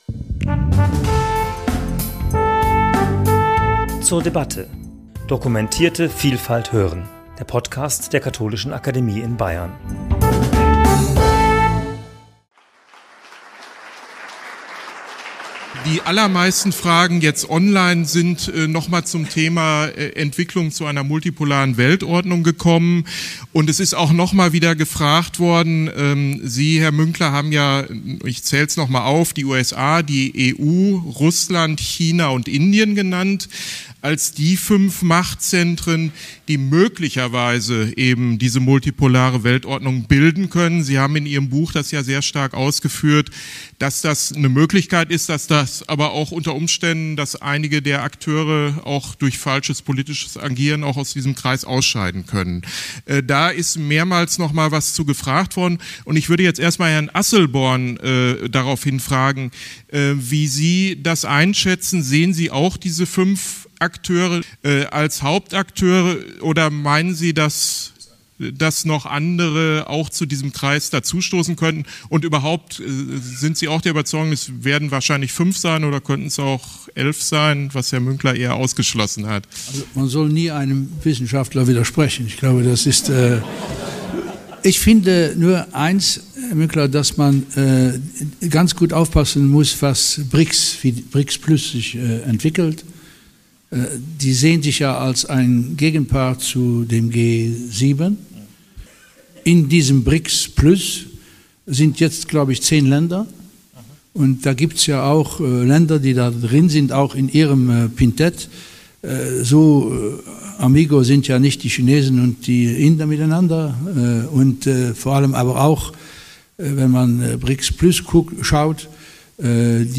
In ihren Referaten analysierten der bekannte Politikwissenschaftler Herfried Münkler und der ehemalige luxemburgische Außenminister und Vollblut-Europäer Jean Asselborn, welche Risiken die sich dramatisch veränderte Weltlage - besonders, aber nicht nur für die EU - mit sich bringt.